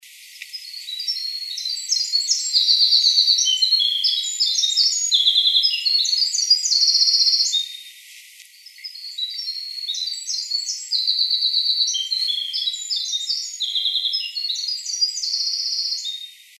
Winter Wren, poor recording
This bird was too far away for a good sound.
Recorded in Near Eagle River, Wisconsin